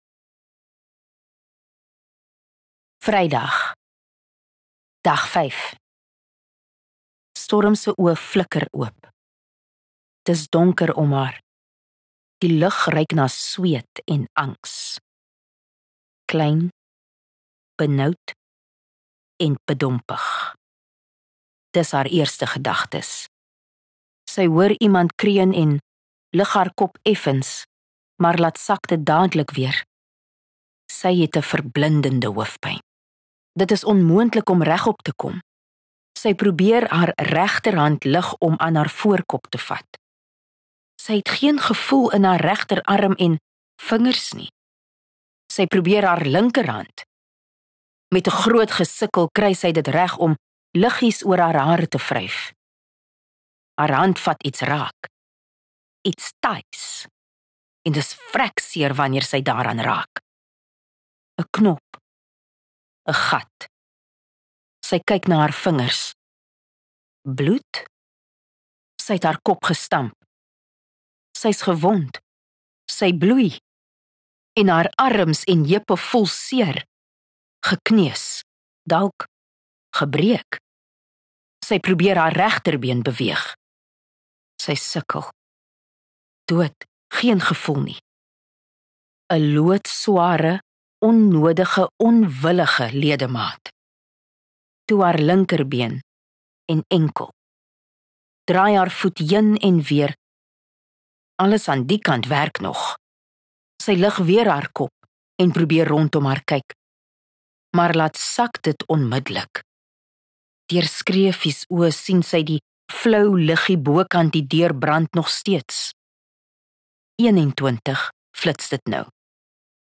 JacPod View Promo Continue JacPod Install Netwerk24 Luisterboek: Kwaaiwater #93 092_Kwaaiwater_Storm se oe flikker oop 3 MIN Download (1.5 MB) AF SOUTH AFRICA 00:00 Playback speed Skip backwards 15 seconds